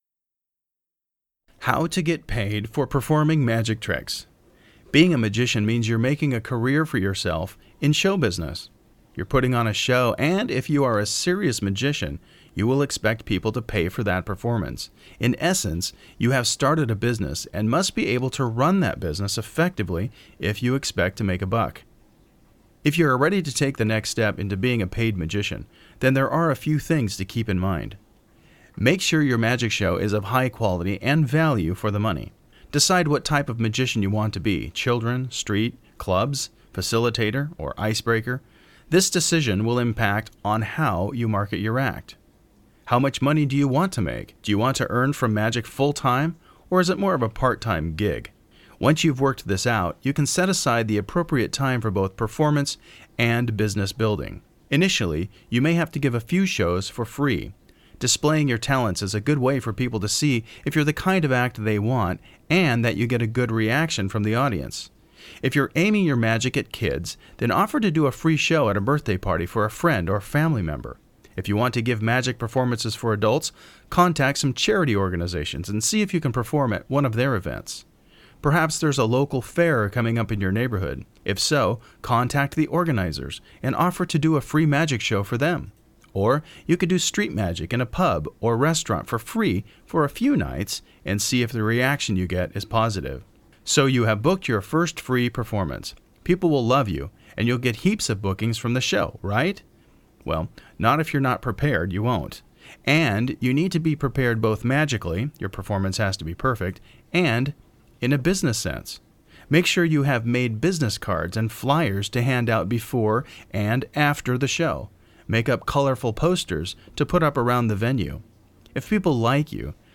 Unadvertised bonus: CLICK HERE to download the e-course audiobook!